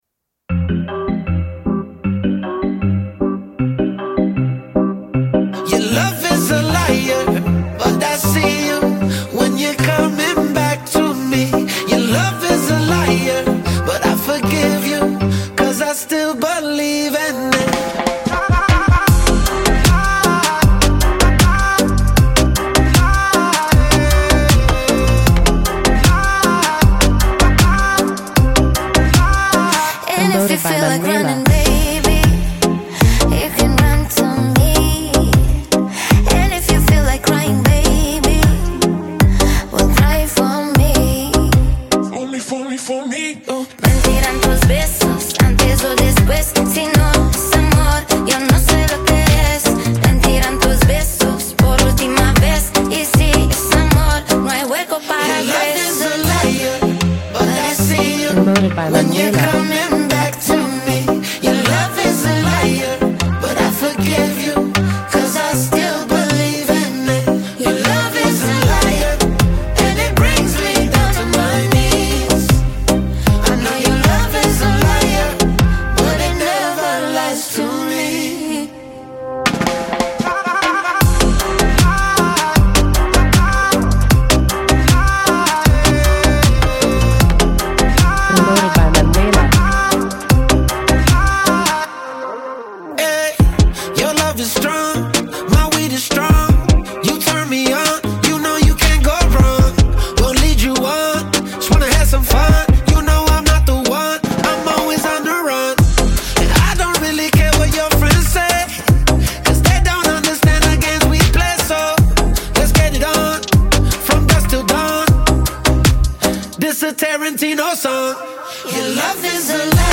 jolie ballade mid-tempo, entraînante et positive